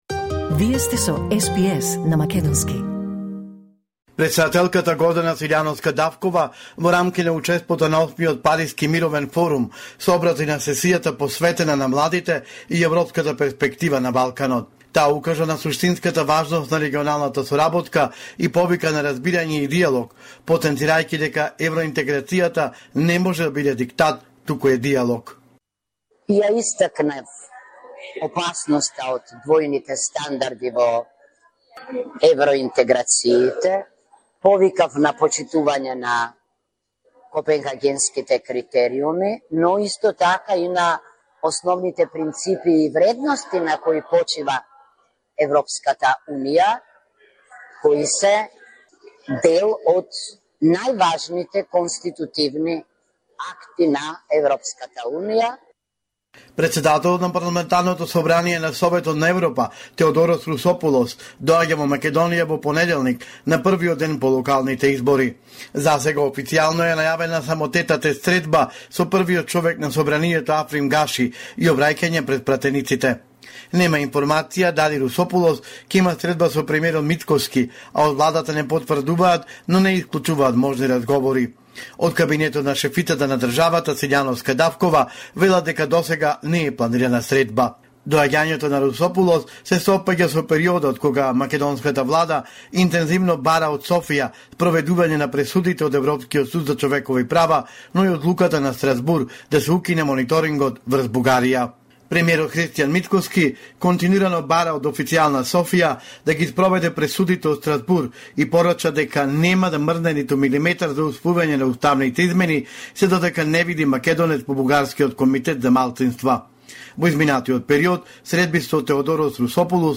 Извештај од Македонија 30 октомври 2025